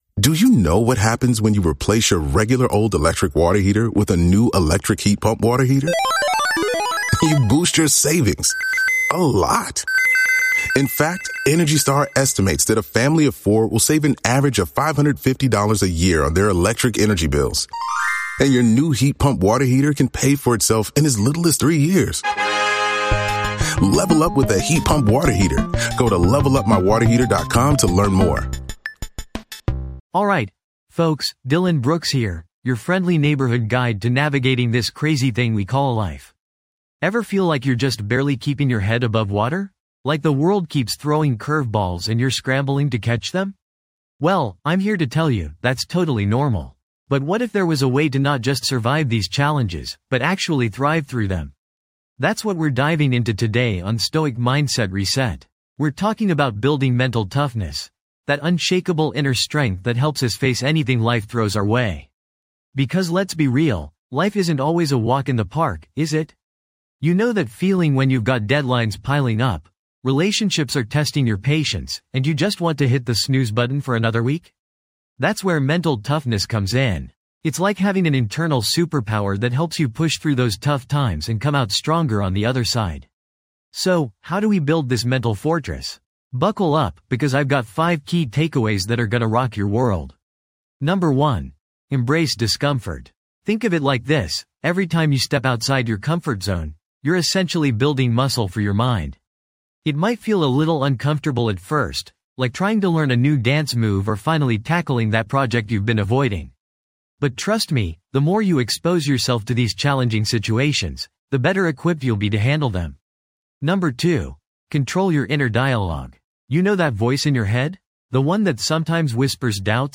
Personal Development & Self-Help, Philosophy, Mental Health, Mindfulness & Meditation, Inspirational Talks
This podcast is created with the help of advanced AI to deliver thoughtful affirmations and positive messages just for you.